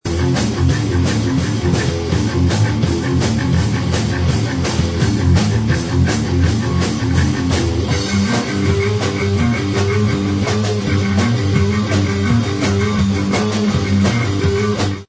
des musiques auto-produites
thrash métal